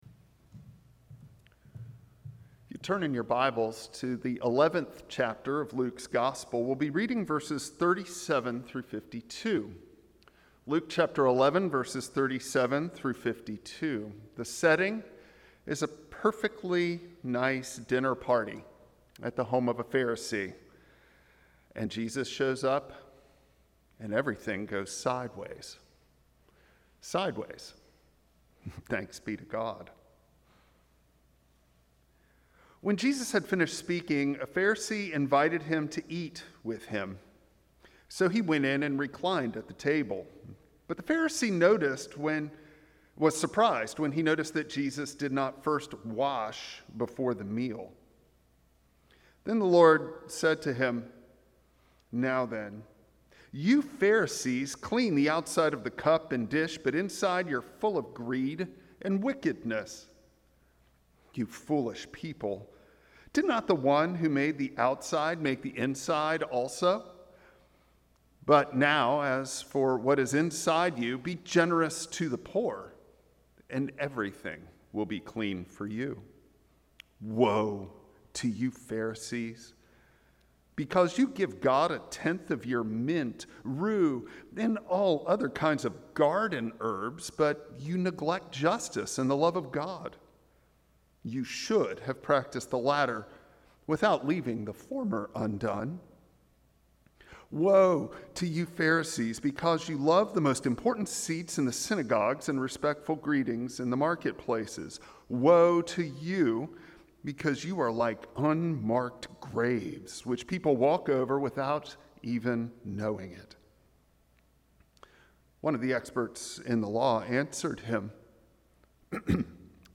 Passage: Luke 11:37-52 Service Type: Traditional Service Bible Text